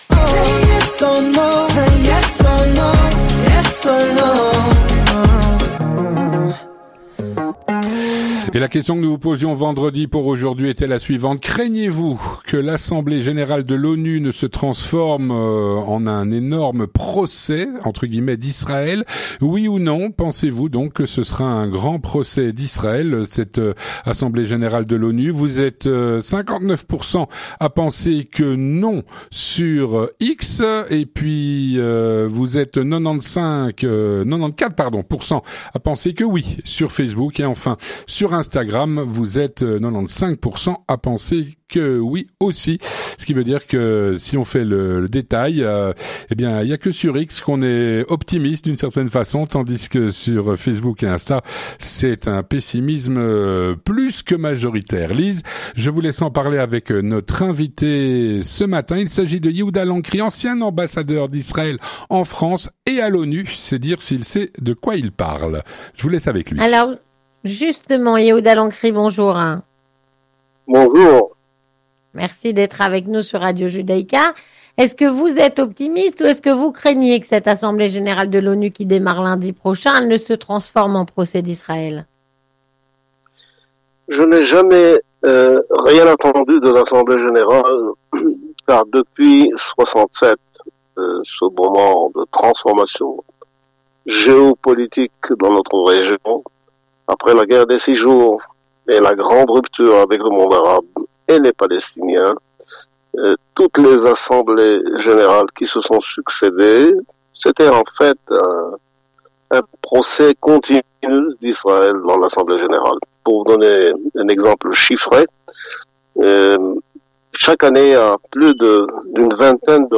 Yehuda Lancry, ancien ambassadeur d’Israël en France et à l’ONU, répond à "La Question Du Jour".